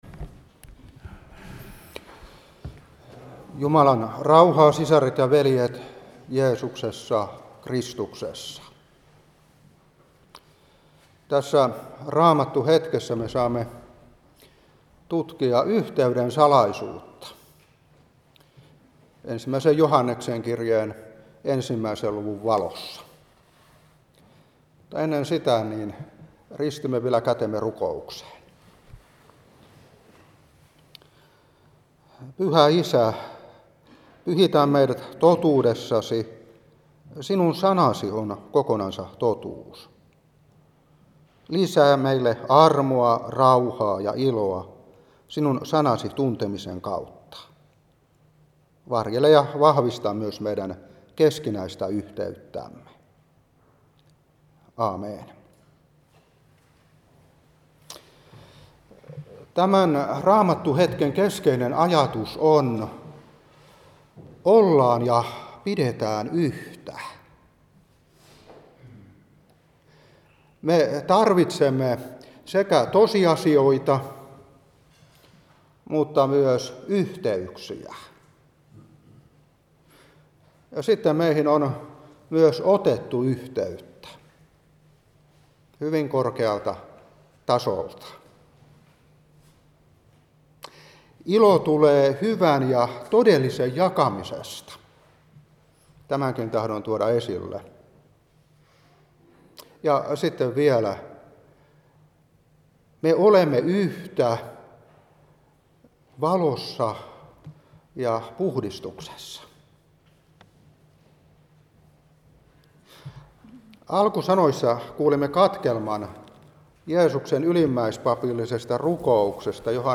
Seurapuhe 2022-9. Joh.17:20,21. 1.Joh.1:1-7.